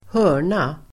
Uttal: [²h'ö:r_na]